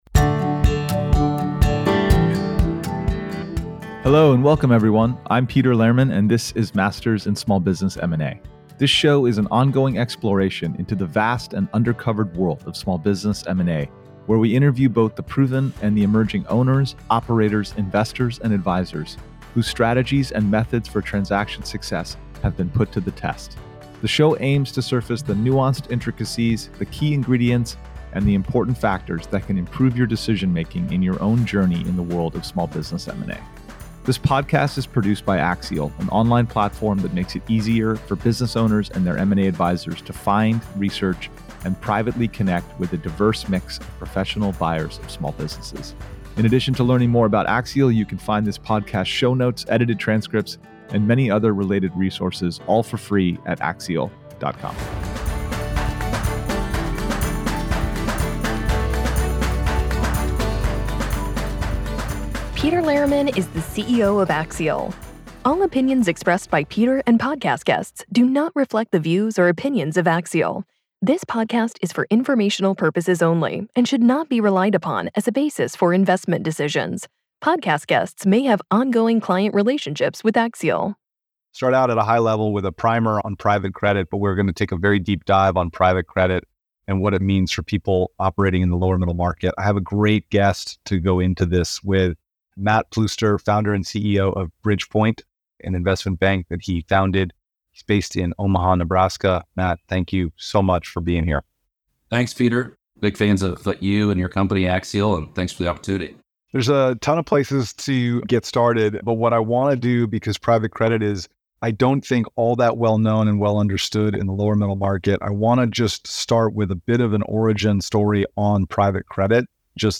Masters in Small Business M&A explores the vast world of small business acquisitions, interviewing a mix of proven and emerging owners, operators, acquirers, and M&A advisors whose strategies and methods are being put to the test.